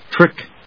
/trík(米国英語)/